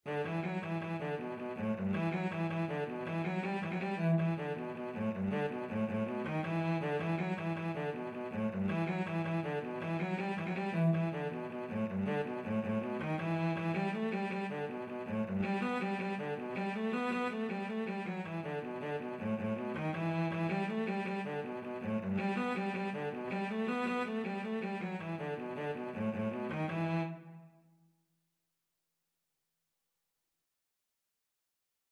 Cello version
9/8 (View more 9/8 Music)
E minor (Sounding Pitch) (View more E minor Music for Cello )
Cello  (View more Easy Cello Music)
Traditional (View more Traditional Cello Music)